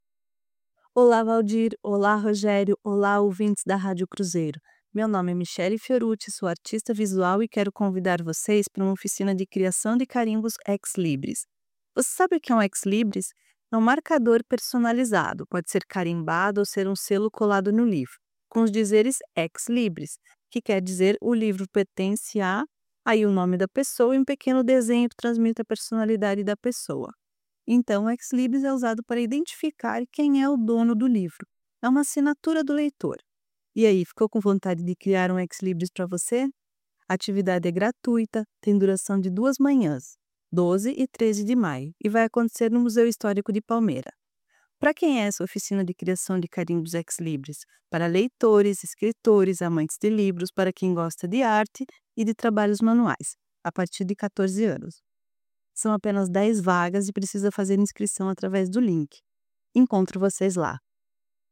A artista visual e ministrante da oficina faz o convite!